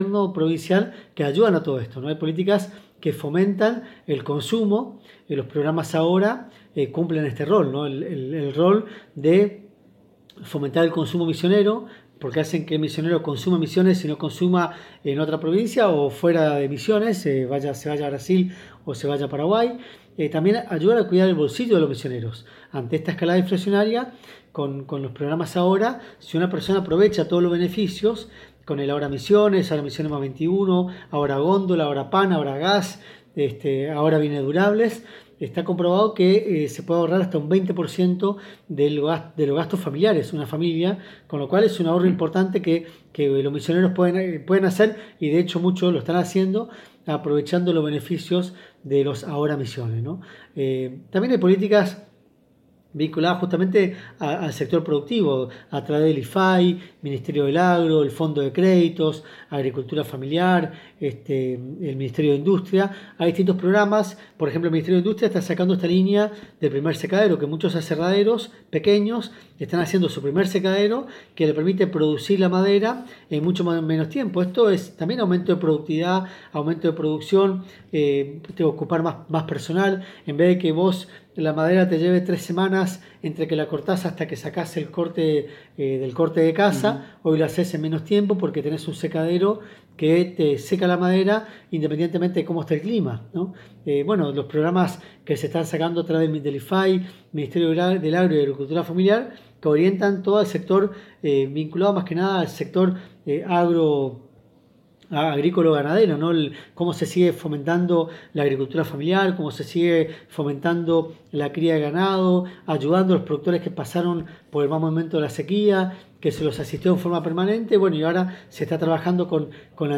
El Ministro de Hacienda de Misiones Adolfo Safrán dialogó en forma exclusiva con la ANG y FM Éxito de Apóstoles y realizó un resumen de la situación económica mundial y Nacional, el por que de la escalada inflacionaria, manifestando que durante la pandemia los gobiernos de todo el mundo debieron imprimir moneda, dinero, para inyectar […]
Ante este panorama el Gobierno de Misiones no ha quedado paralizado ante estos eventos sino que ha llevado adelante acciones y líneas de Gobierno para darle batalla verdadera a la inflación. En el audio el Ministro Safrán explica las acciones del Gobierno de la Provincia.